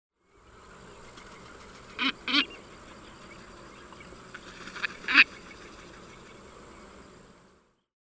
Cuervo de Pantano
Plegadis chihi
Plegadis-chihi.mp3